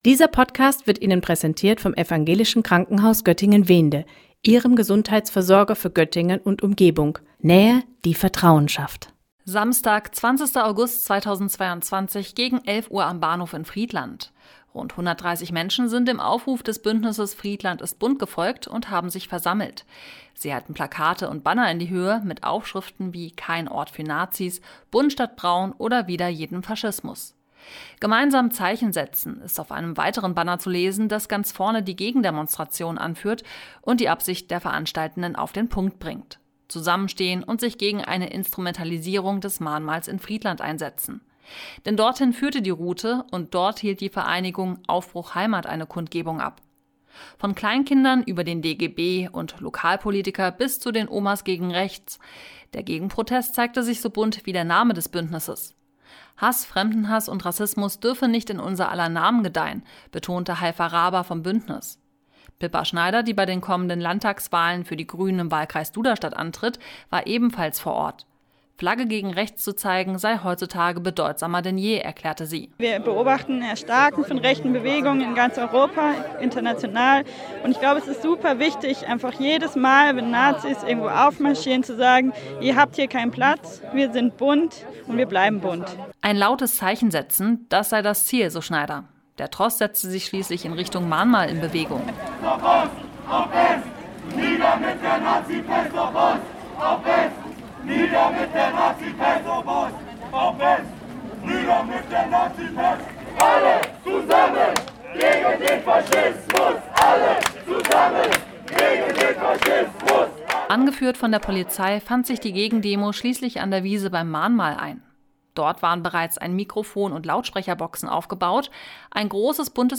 O-Ton 2, Atmo Gegenprotest, 18 Sekunden